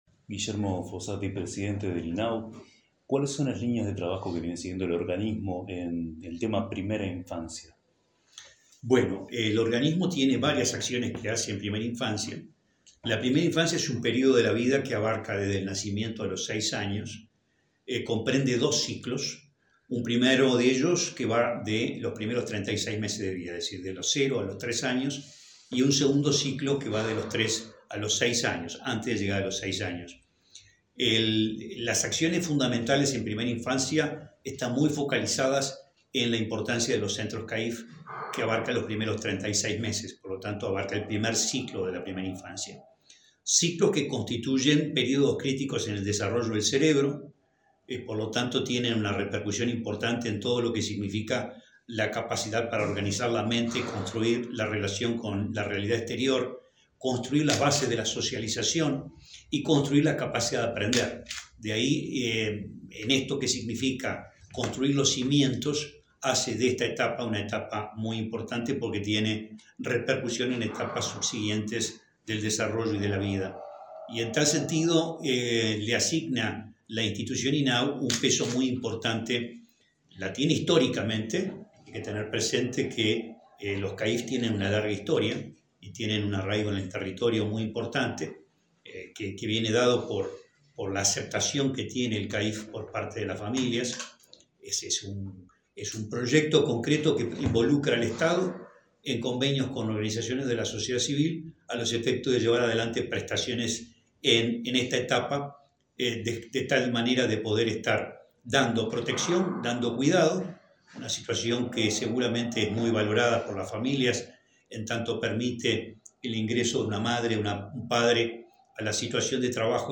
Entrevista al presidente del INAU, Guillermo Fossatti